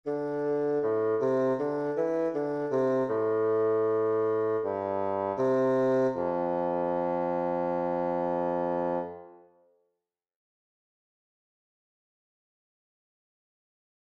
Basson
Famille : vent/bois
C’est un instrument plutôt grave, équivalent au violoncelle dans la famille des cordes frottées.